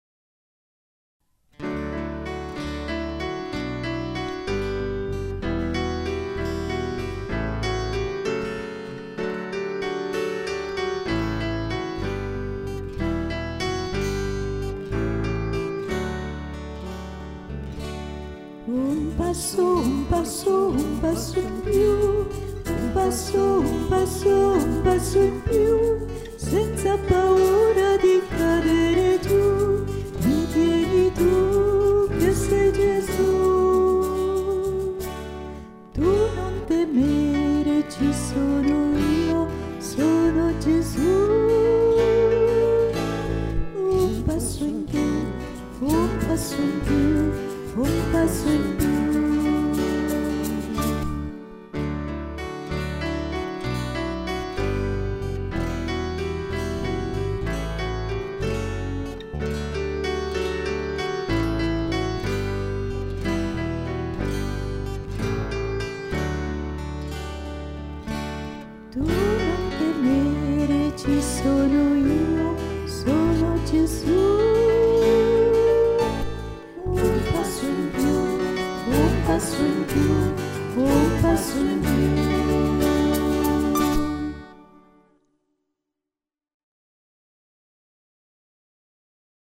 n. 7 Un passo in più canto mp3 Un passo in più Un passo, un passo, un passo in più (2 v.)